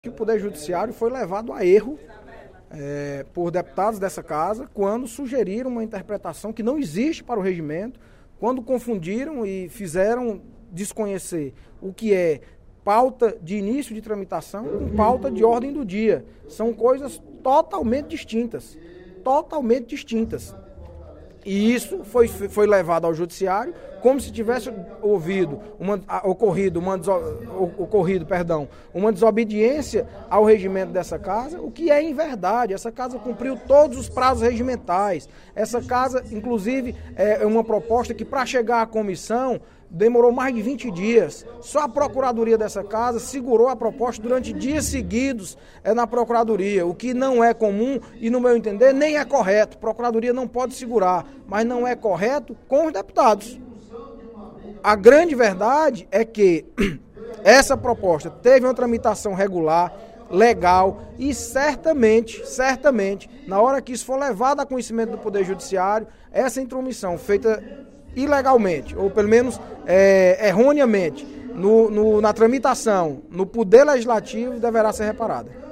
O deputado Audic Mota (PMDB) discordou, durante o primeiro expediente da sessão plenária desta quarta-feira (14/06), da decisão do juiz de direito Carlos Rogério Facundo, do Juizado Especial da Fazenda Pública.
Em aparte, o deputado Roberto Mesquita (PSD) enalteceu os esclarecimentos do colega, mas sugeriu uma maior reflexão sobre a Lei Federal n° 12.153, de dezembro de 2009, que dispõe sobre os Juizados Especiais da Fazenda Pública, no âmbito dos estados, do Distrito Federal, dos territórios e dos municípios.